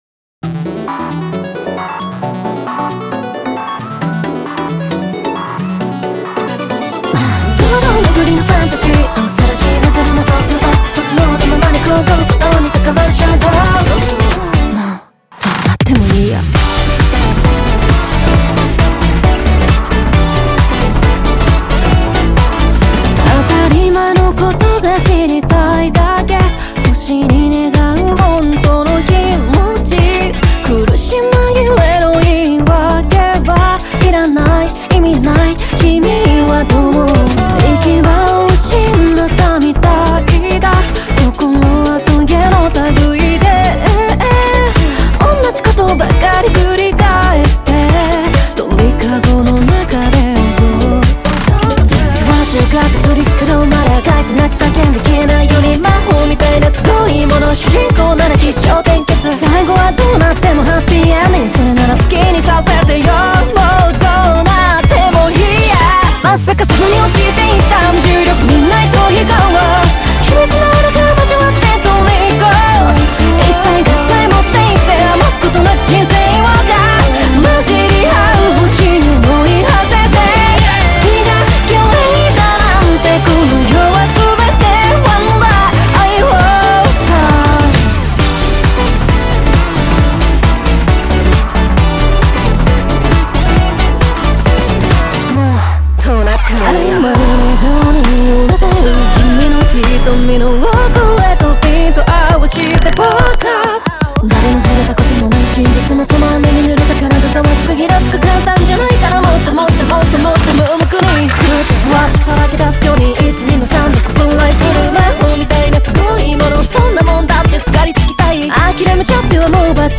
Label JPop